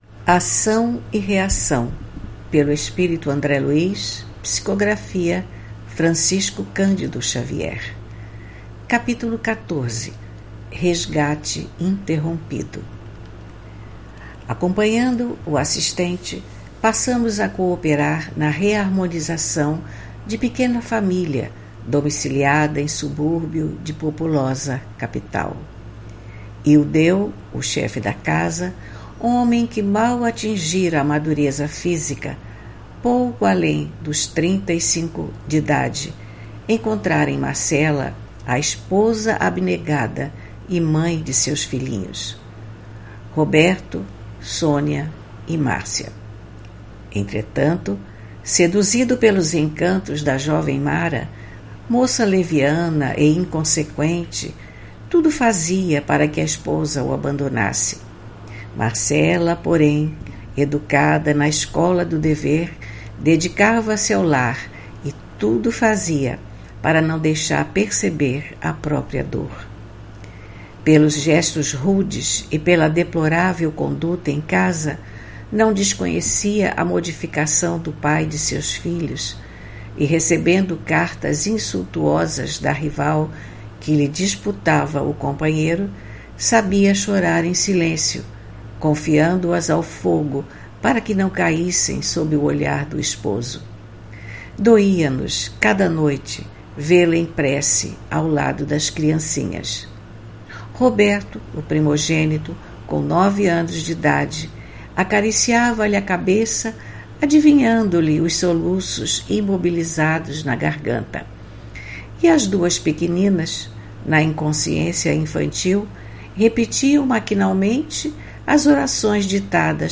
Leitura do livro: Ação e reação, autoria do espírito André Luiz, psicografia de Francisco Candido Xavier.